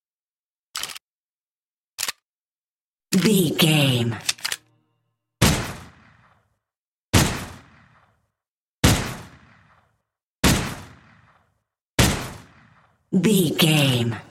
Filled with 10 sounds(44/16 wav.) of Pistol Equip, clip eject, Insert, Reload, Firing(Five single shots) and Unequip.
Pistol Equip, Clip eject, Insert, Reload, Firing and Unequip 03
Sound Effects
Adobe Audition, Zoom h4
Guns Weapons